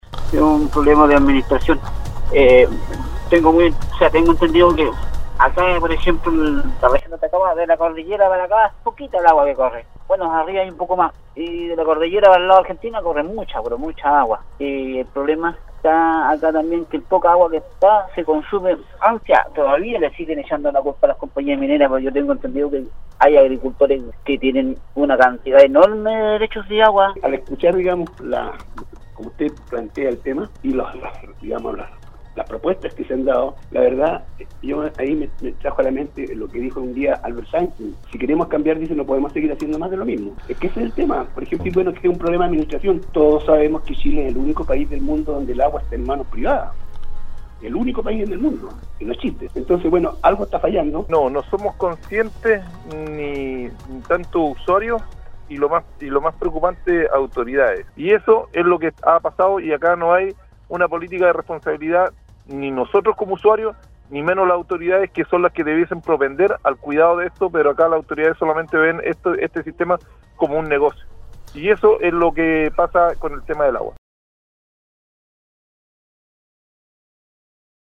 En relación al tema, auditores de Nostálgica participaron en el foro del programa Al Día para opinar sobre si somos conscientes o no al grave problema que nos aqueja en el norte del país.